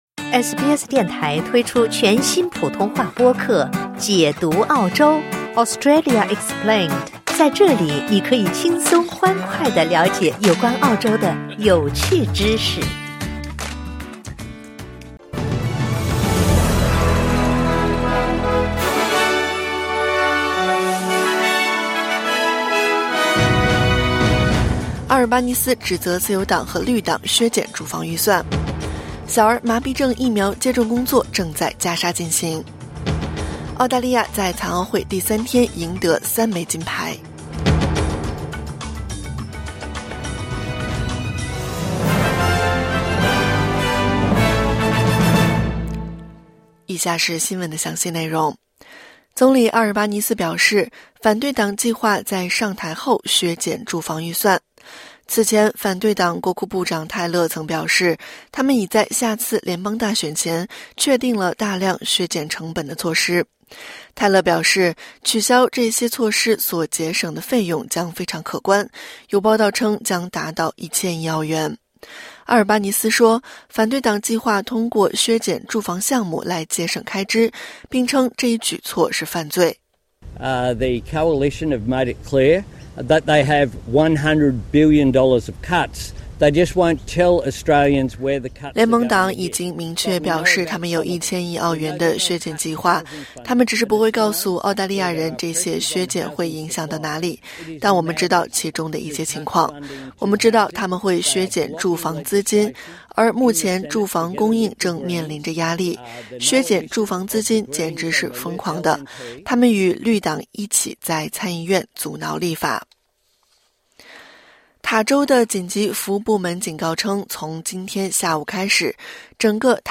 SBS早新闻（2024年9月1日）
SBS Mandarin morning news Source: Getty / Getty Images